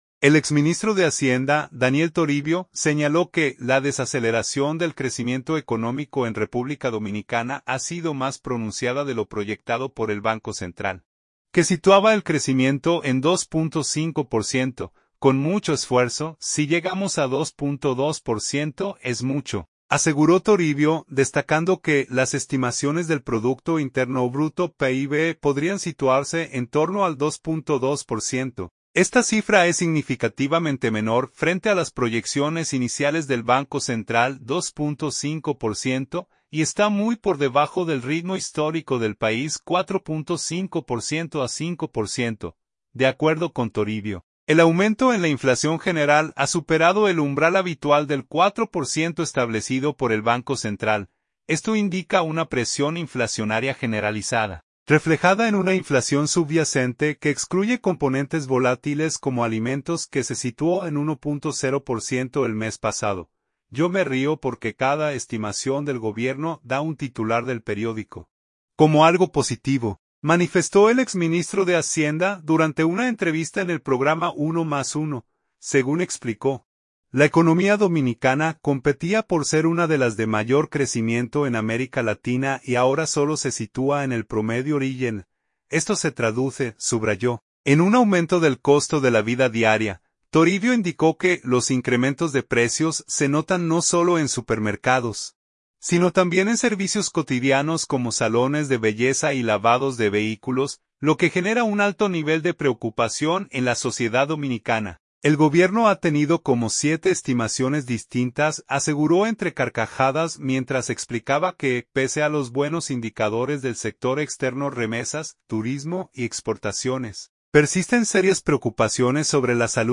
“Yo me río porque cada estimación del gobierno da un titular del periódico como algo positivo”, manifestó el exministro de Hacienda durante una entrevista en el programa “Uno Más Uno”.
“El Gobierno ha tenido como siete estimaciones distintas”, aseguró entre carcajadas mientras explicaba que, pese a los buenos indicadores del sector externo (remesas, turismo y exportaciones), persisten serias preocupaciones sobre la salud económica interna de República Dominicana, especialmente en lo referente a la inflación y la gestión del gasto público.